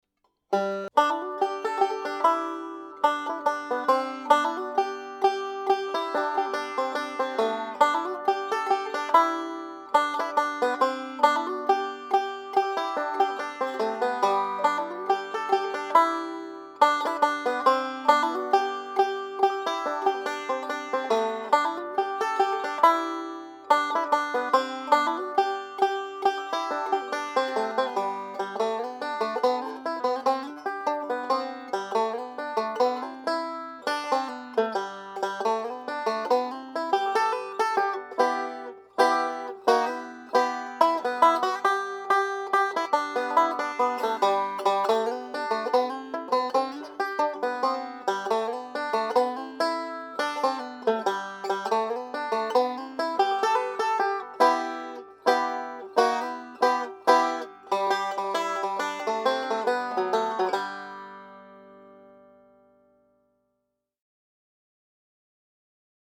5-STRING BANJO SOLO Three-finger melodic style
DIGITAL SHEET MUSIC - 5-STRING BANJO SOLO
learning speed and performing speed